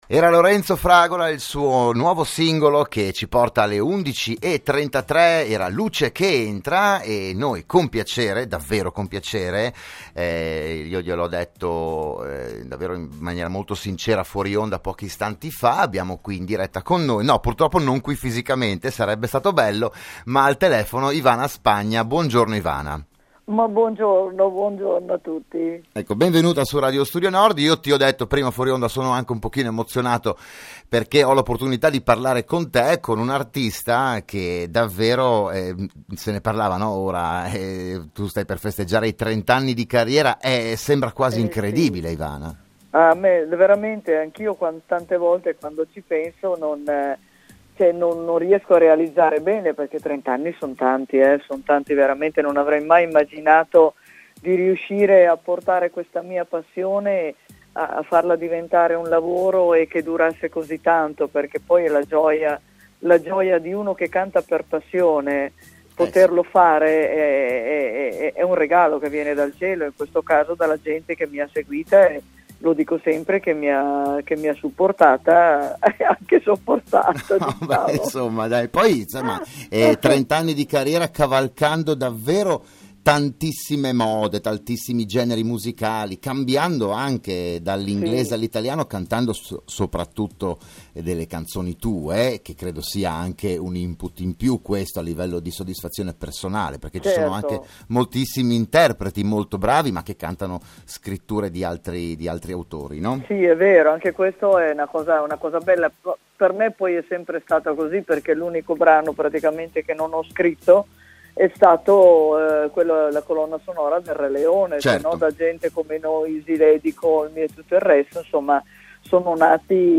Collegata al telefono c’era Ivana Spagna, che nella sua lunga carriera vanta superhit come “Easy Lady”, “Call me”, “Every girl and boy”, “Gente come noi” e “Come il cielo”, attualmente nella programmazione musicale di Studio Nord con “D.A.N.C.E.”.